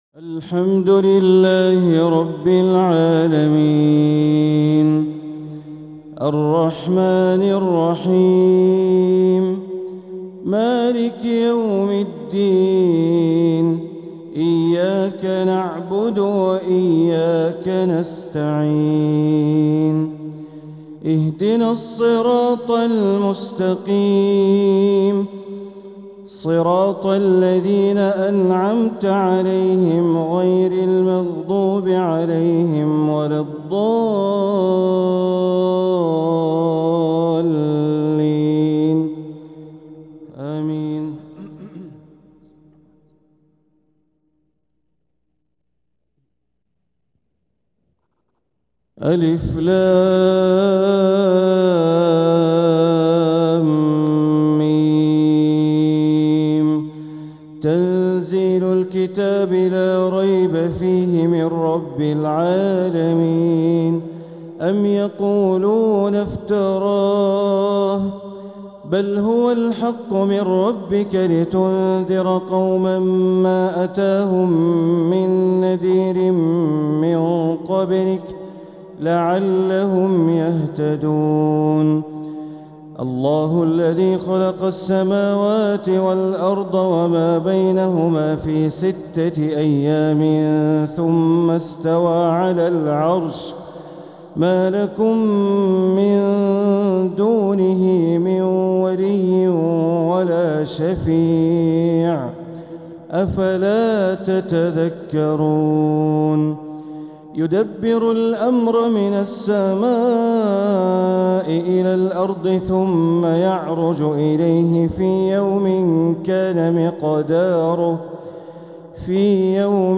تلاوة لسورتي السجدة والإنسان | فجر الجمعة ٢٠ربيع الأول ١٤٤٧ > 1447هـ > الفروض - تلاوات بندر بليلة